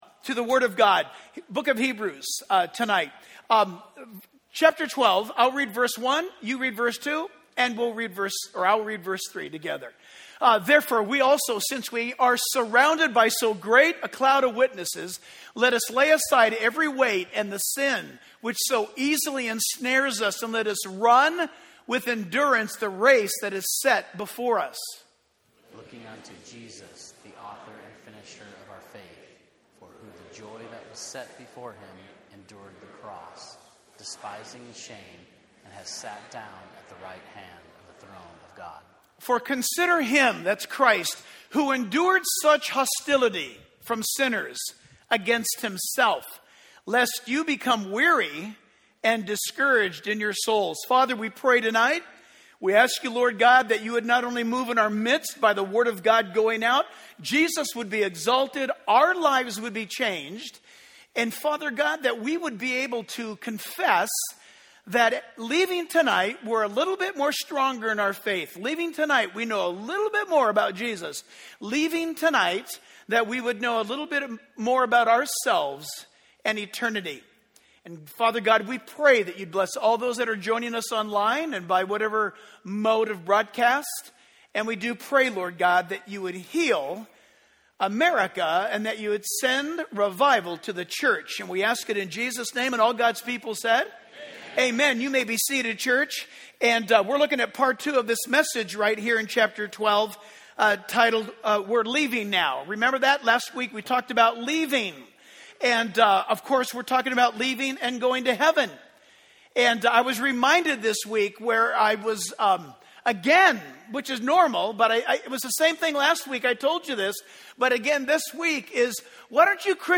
Reference: Hebrews 12:1-3 Download Sermon MP3 Download Sermon Notes